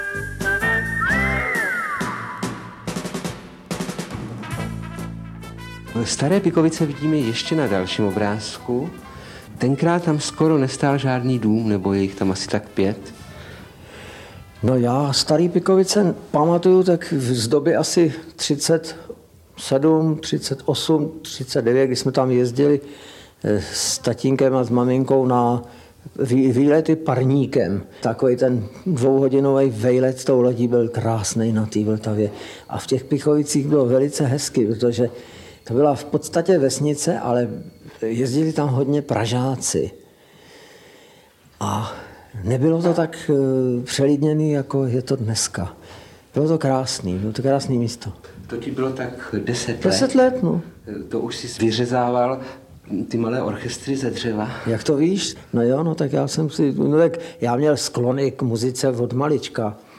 Audiobook
Audiobooks » Humour, Satire & Comedy